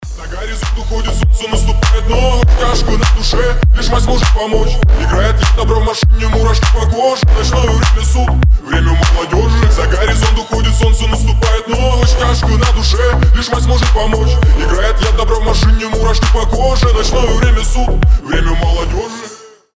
• Качество: 320, Stereo
Club House
качающие
ремиксы